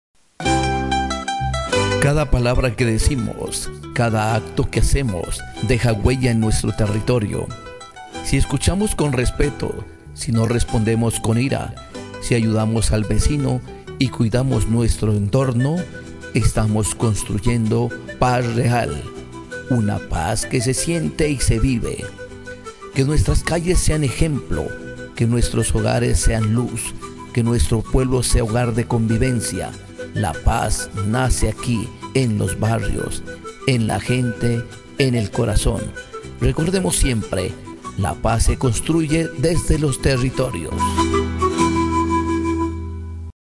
PERIFONEOS
Esta serie de audios recoge el perifoneo callejero como estrategia de comunicación territorial y comunitaria. Desde las calles, la voz amplificada informa, convoca y fortalece los vínculos sociales en el territorio.